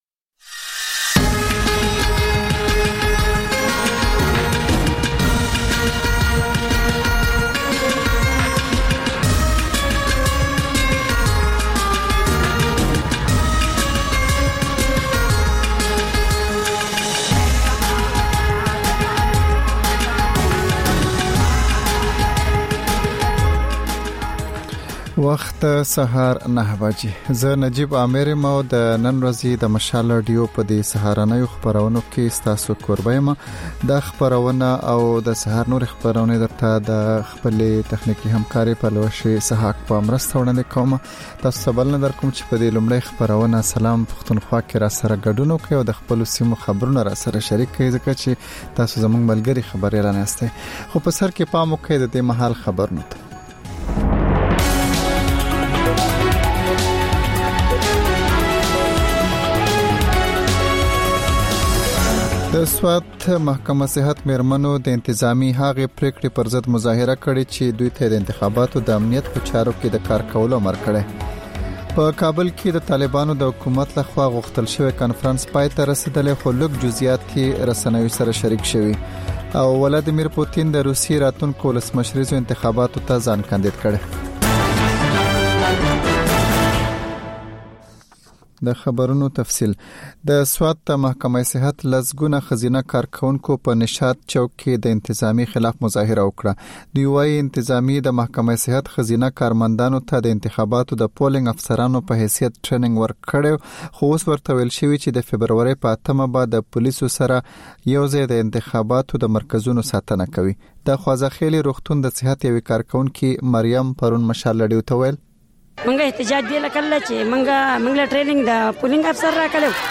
دا د مشال راډیو لومړۍ خپرونه ده چې پکې تر خبرونو وروسته رپورټونه، له خبریالانو خبرونه او رپورټونه او سندرې در خپروو.